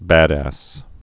(bădăs) Vulgar Slang